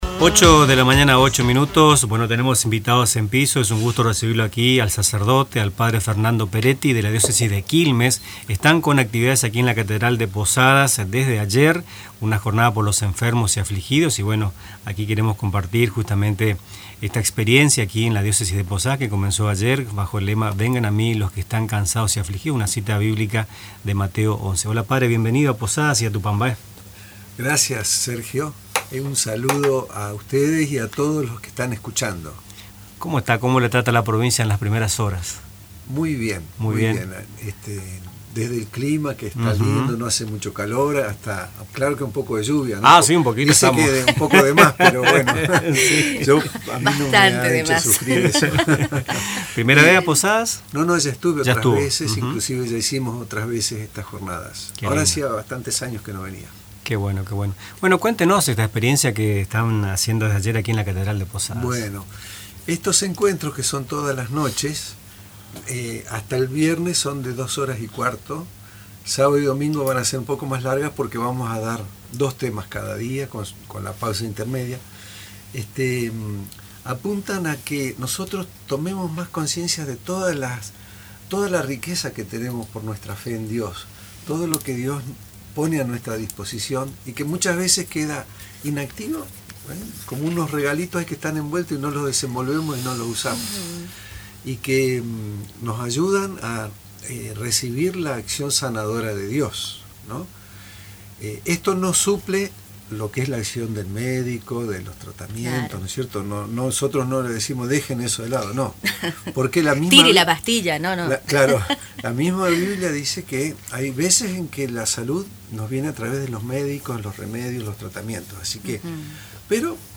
visitó hoy los estudios de Radio TupaMbaé con el propósito de extender una afectuosa invitación a la comunidad.